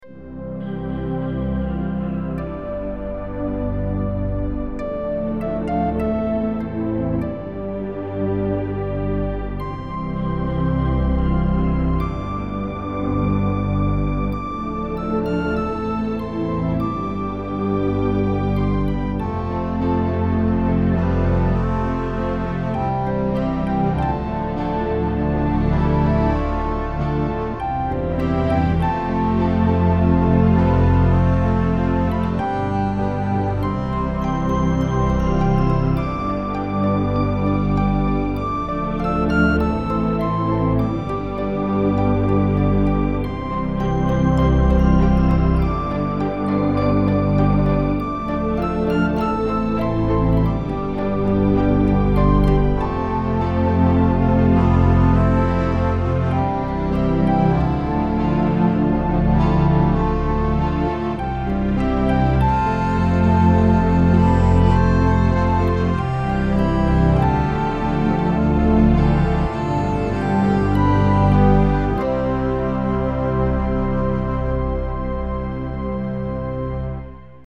Orchestral Pieces